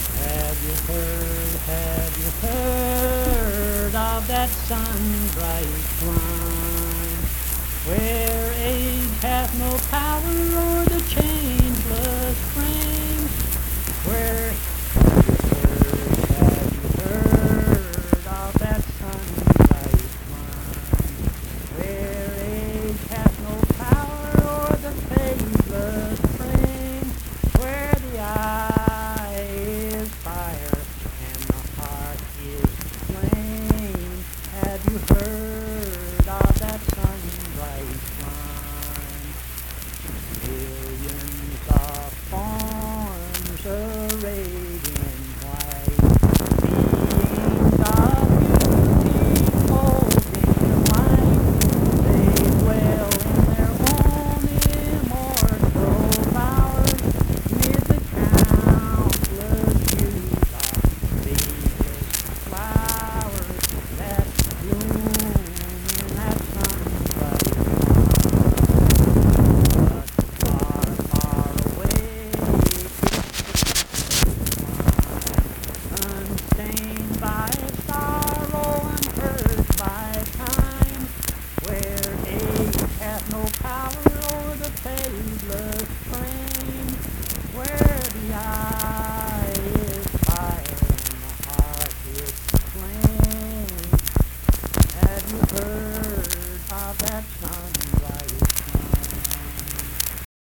Accompanied (guitar) and unaccompanied vocal music
Verse-refrain 3(5w/R). Performed in Mount Harmony, Marion County, WV.
Hymns and Spiritual Music
Voice (sung)